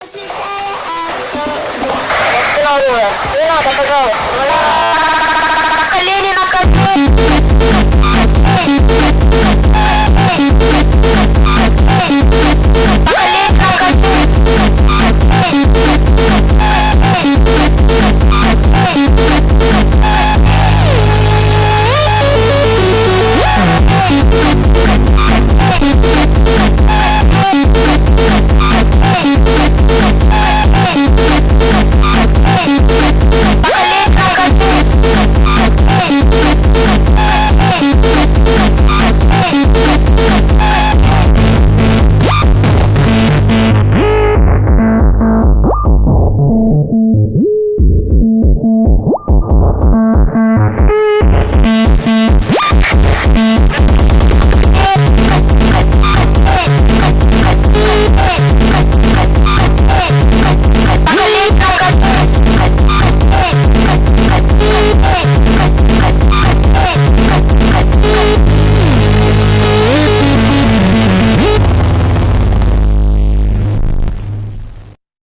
Жанр: Узбекские песни Слушали